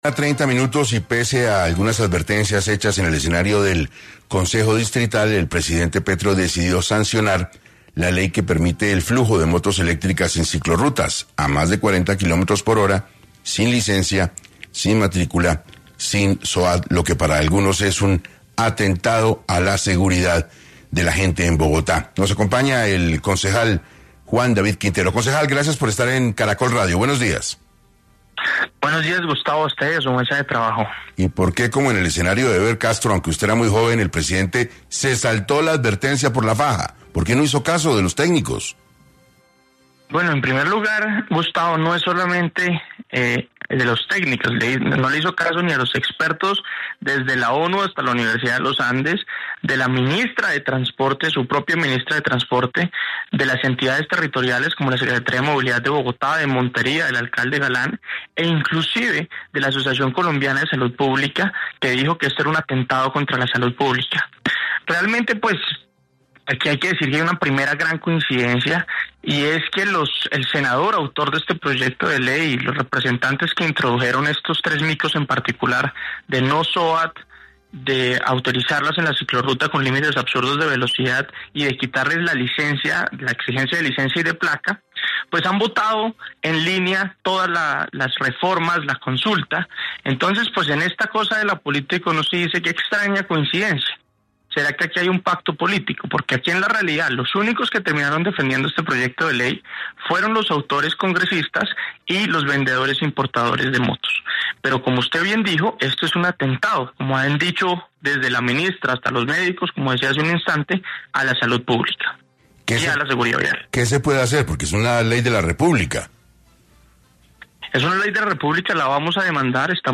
En 6AM de Caracol Radio estuvo el concejal Juan David Quintero, quien habló tras la decisión del presidente de la república de sancionar la ley de tránsito de motos sin licencia, sin soat y sin placa.